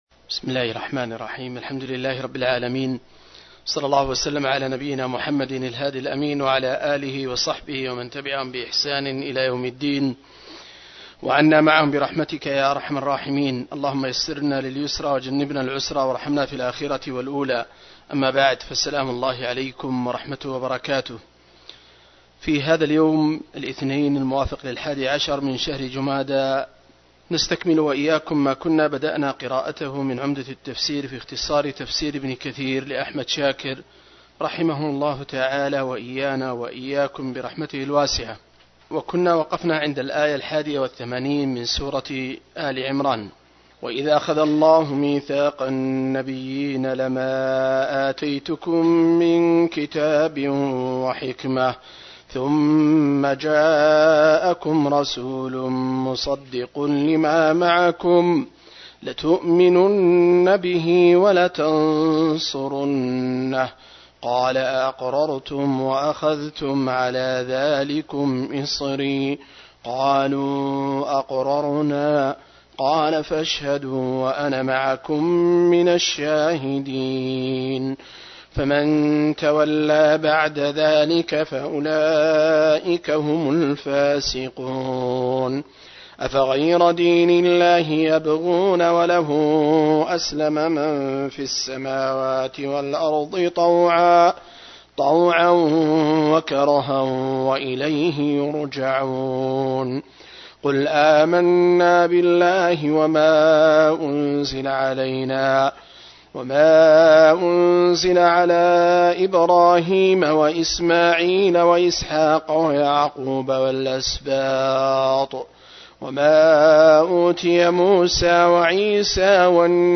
069- عمدة التفسير عن الحافظ ابن كثير رحمه الله للعلامة أحمد شاكر رحمه الله – قراءة وتعليق –